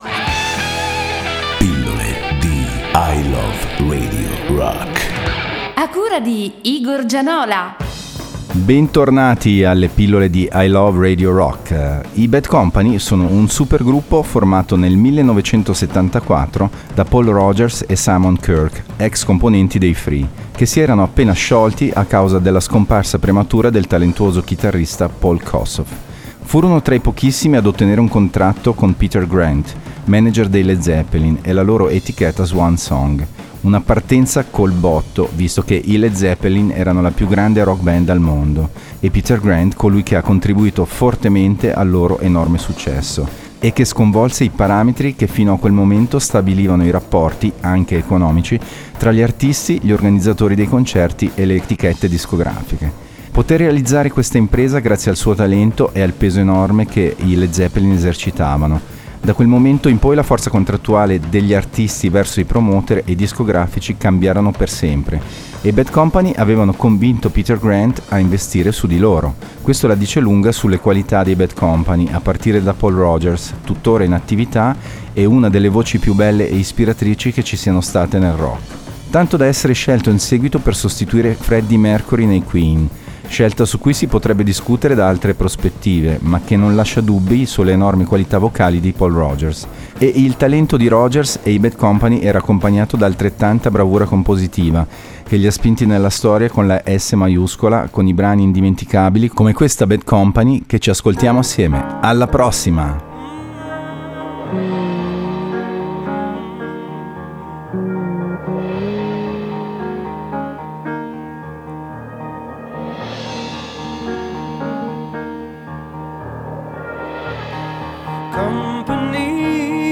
hard rock e heavy metal
ROCK